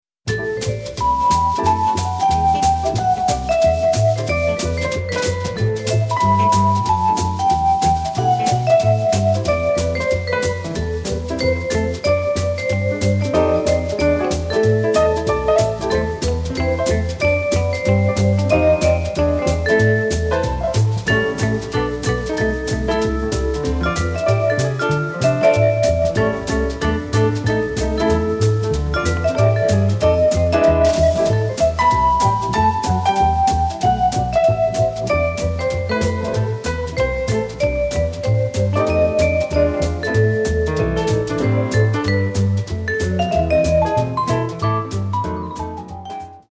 Crime Jazz at its best!